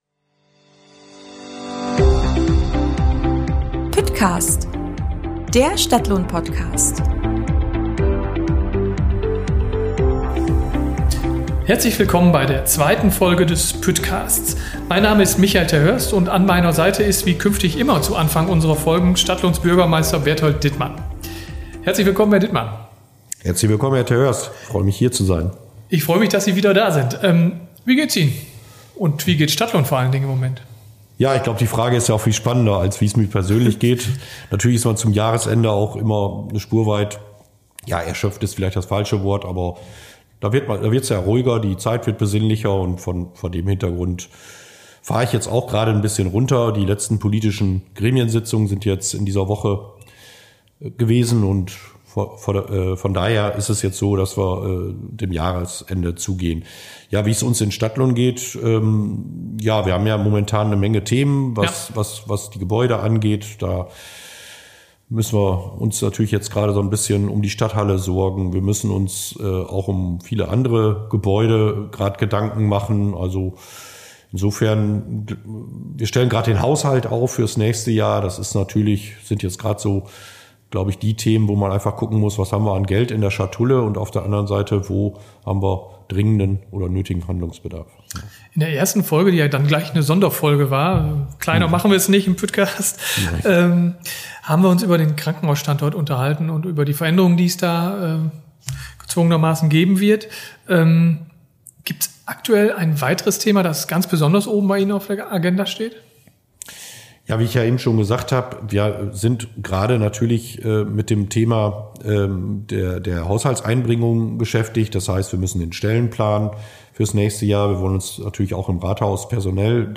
Im Einstiegsgespräch verrät Bürgermeister Berthold Dittmann, was die Stadt aktuell bewegt. Er weist auf aktuelle Entwicklungen in der Verwaltung hin (die Umstrukturierung der örtlichen Wirtschaftsförderung, die Anstellung eines Juristen/einer Juristin u.a.) und er spricht über Dinge, die ihn als Bürgermeister zuletzt besonders gefreut und geärgert haben.